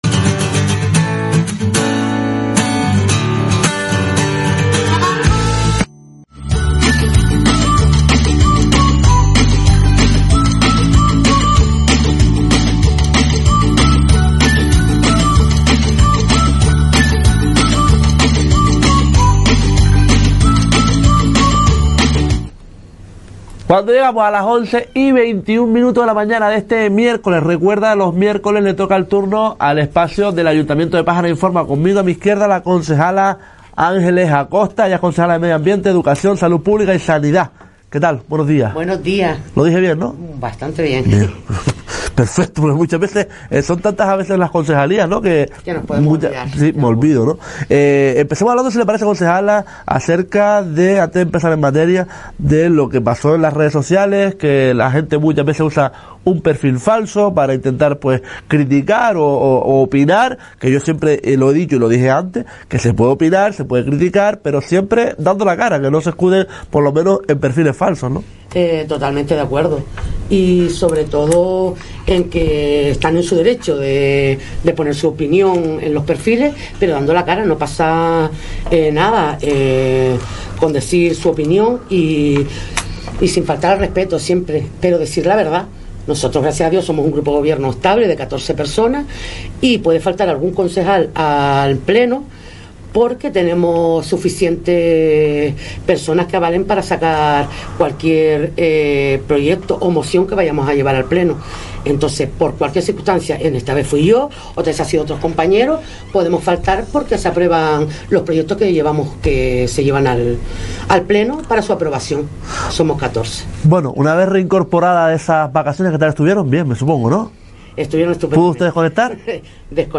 Entrevista a Ángeles Acosta 22/11/23